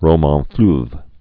(rō-mäɴflœv)